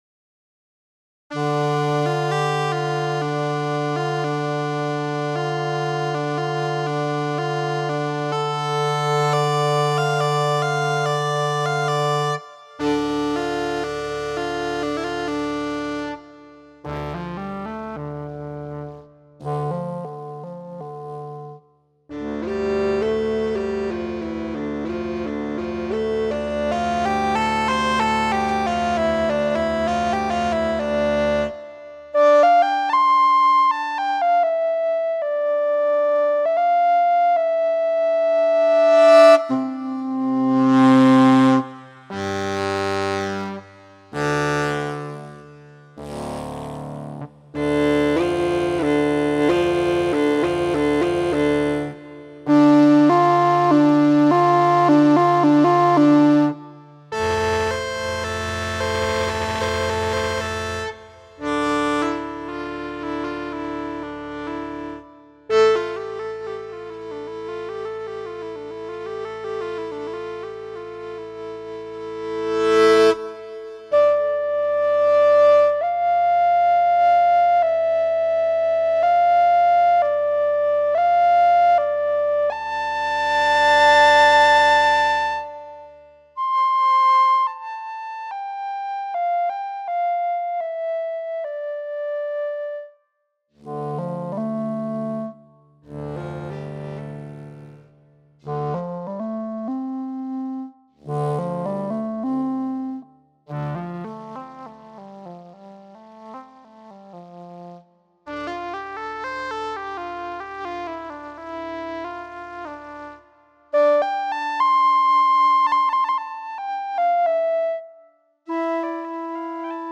A quartet of Respiro voices selected randomly 2 at a time one to be the lead and one to be the drone in an EWI style hold every phrase.